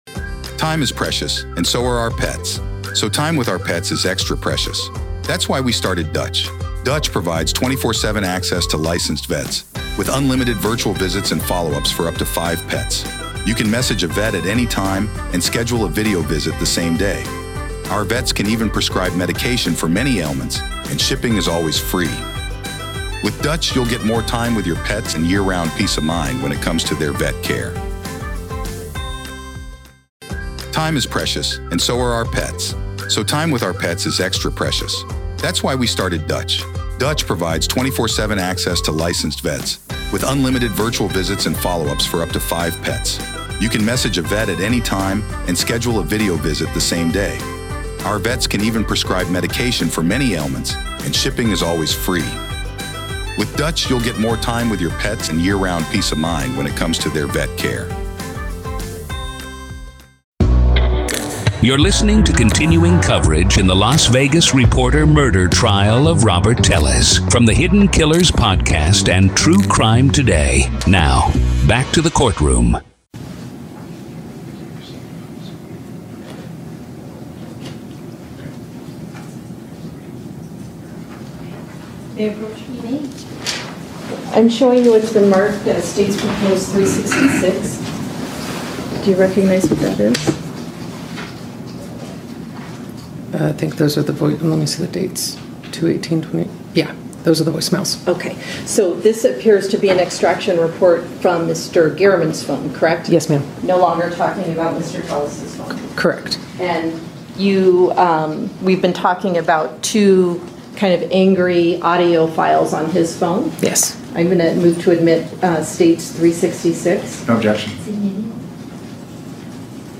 Court Audio-NEVADA v. Robert Telles DAY 4 Part 3